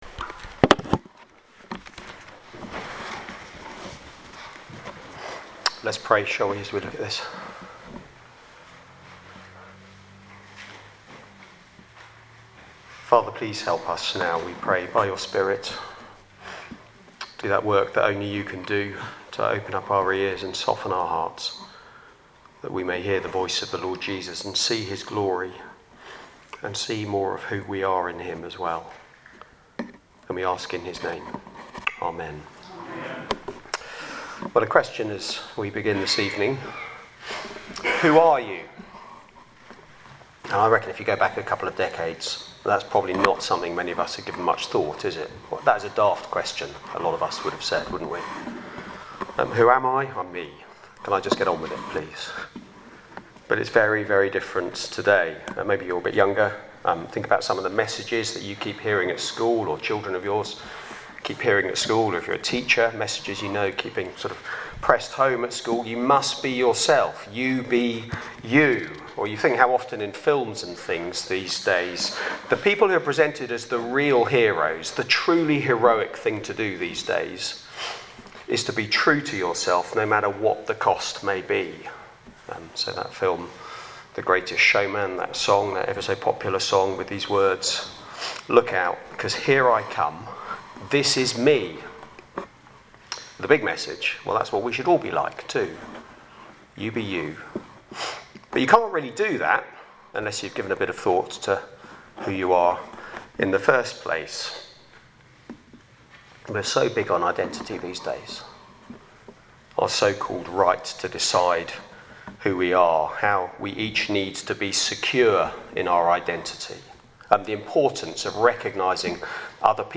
Media for Sunday Evening on Sun 23rd Feb 2025 18:00
Sermon